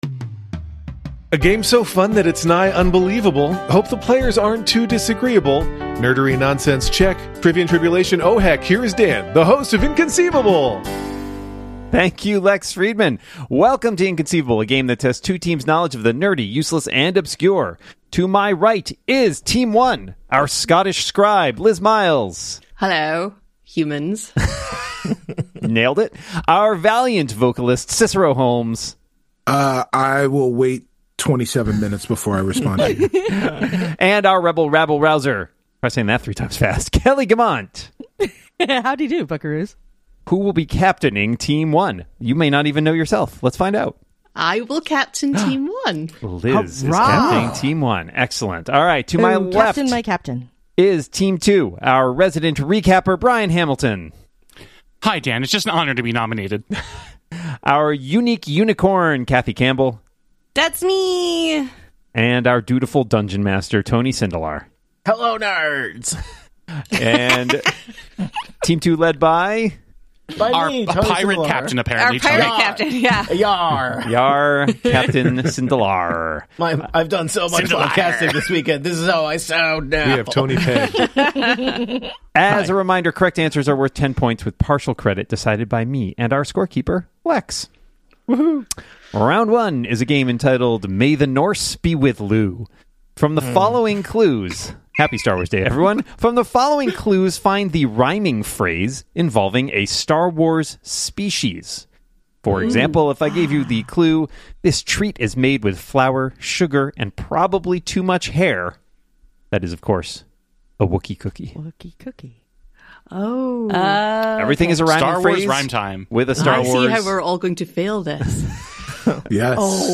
Welcome back to “Inconceivable!”, the show that tests two teams’ knowledge of the nerdy, the useless, and the obscure.